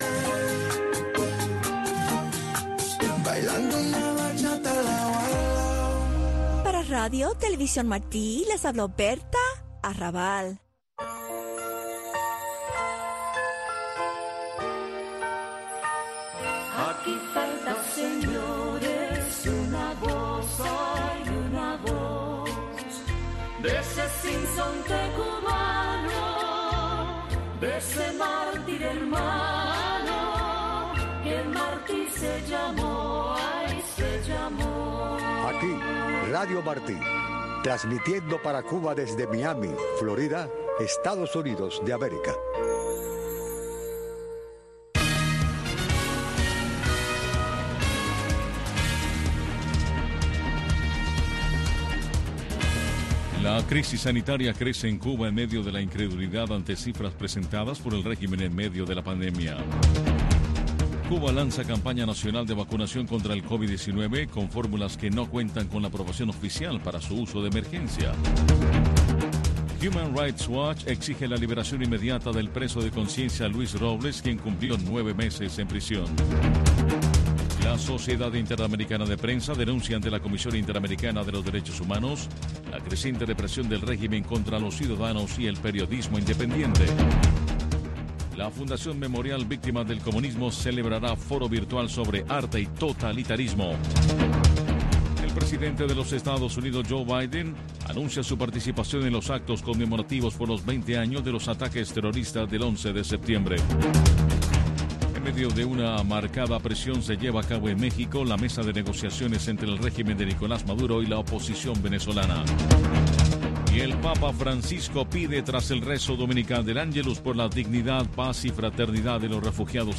Noticiero de Radio Martí